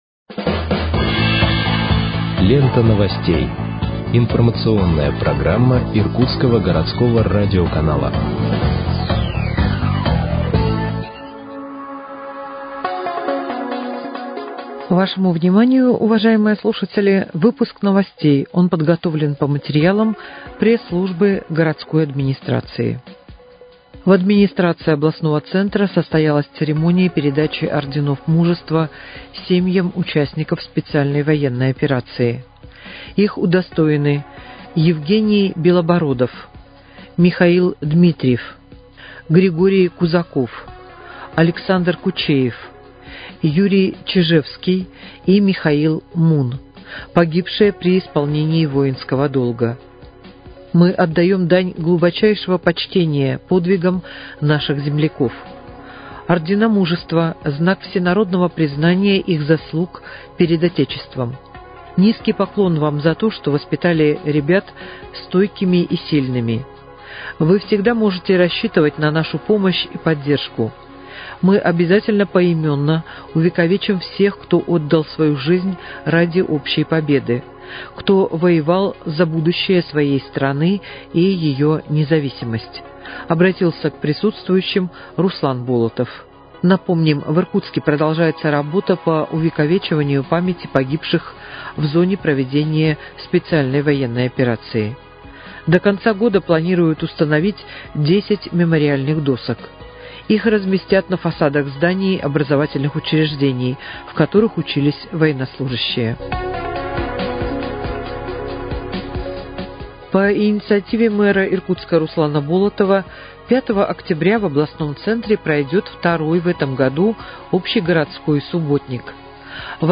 Выпуск новостей в подкастах газеты «Иркутск» от 30.09.2024 № 1